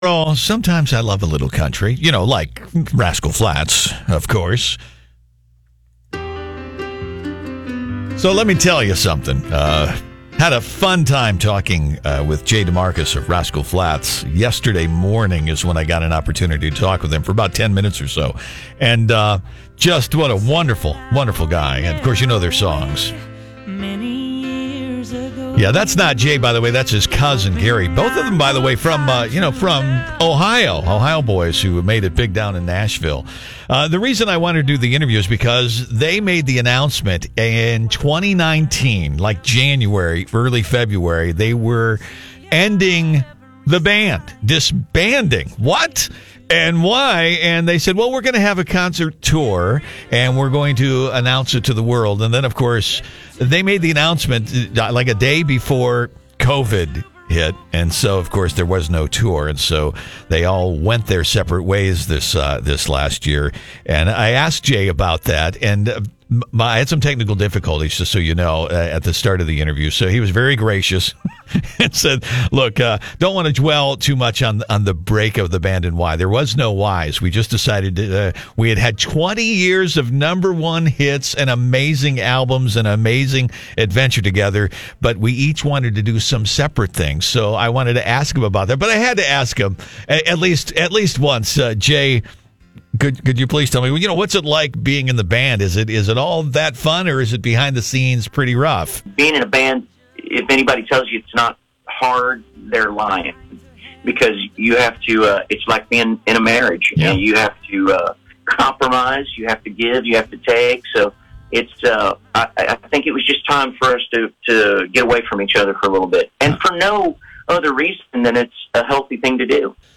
Jay Demarcus with Rascal Flatts Talks “The Breakup” and New Directions